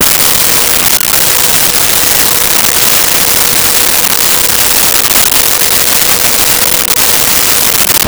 Printer Dot Matrix 01
Printer Dot Matrix 01.wav